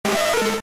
Cri de Nidoran♀ K.O. dans Pokémon Diamant et Perle.